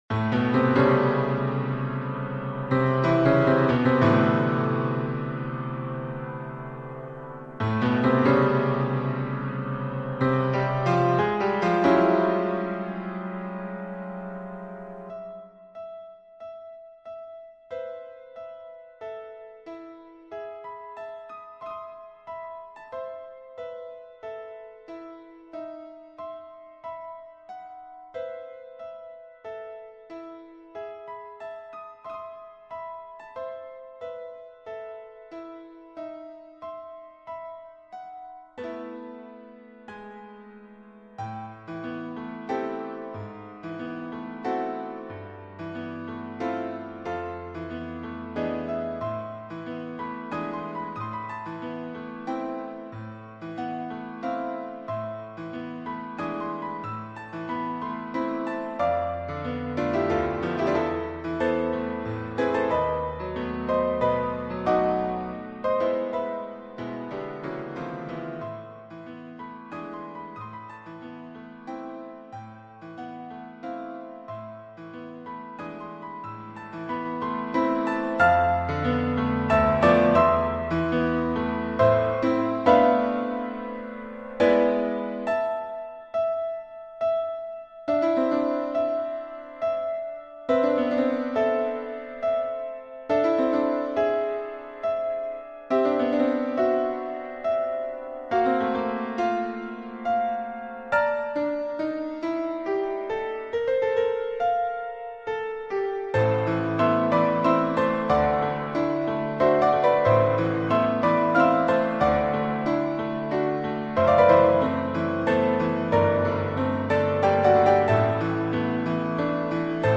piano
həm hüzünlü, həm də sarsılmaz bir zənginliklə çalınır.